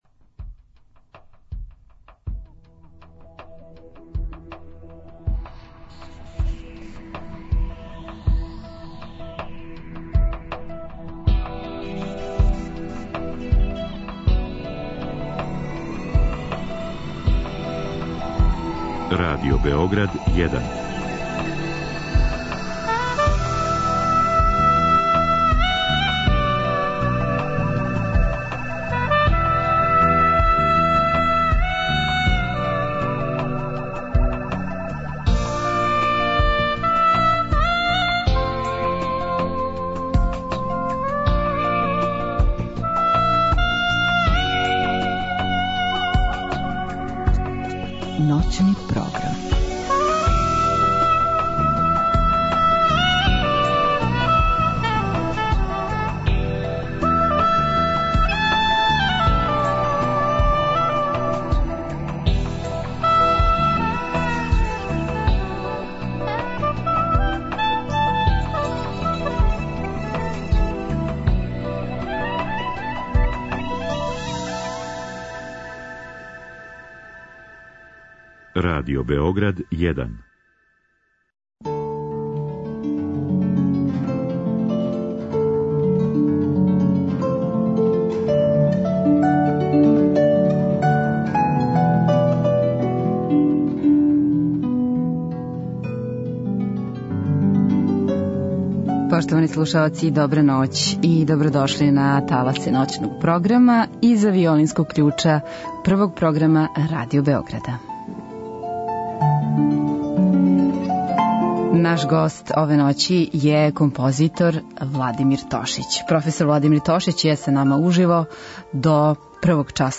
У наставку емисије слушаћемо композиције шведских аутора: Ингве Хелда, Адолфа Виклунда и Андреаса Халена. У последњем сату слушамо дела Шарла Гуноа и Жоржа Бизеа.